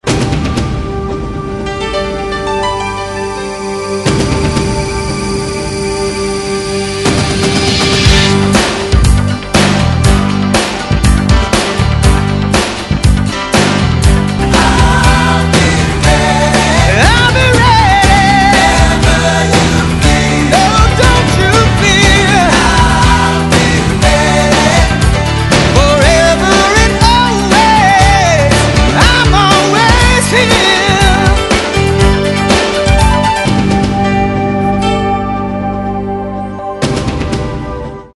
Znelka